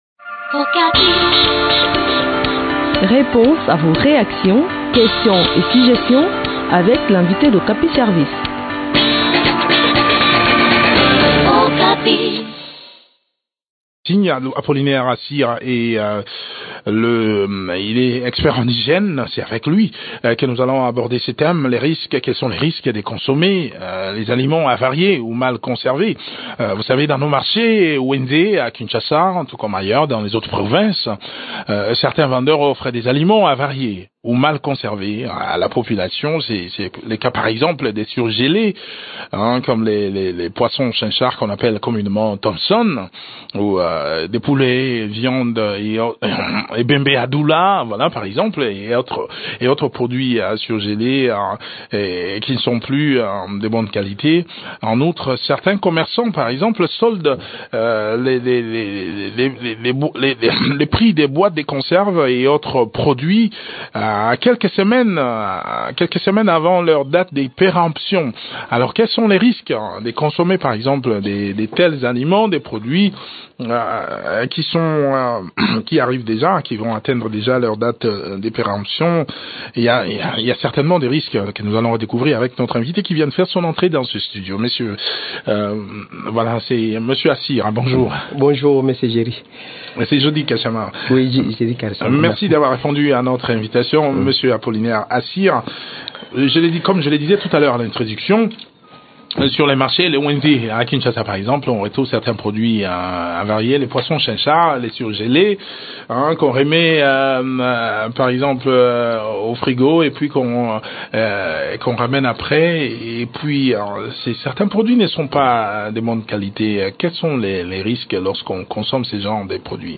expert en hygiène.